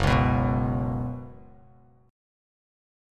Gbsus4 chord